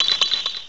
[audio] resample oversampled cries to 13379Hz